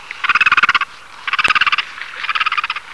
Silberreiher (Egretta alba)
Stimme: schnarrende und krächzende Laute.
Egretta.alba.wav